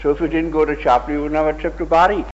the-phonology-of-rhondda-valleys-english.pdf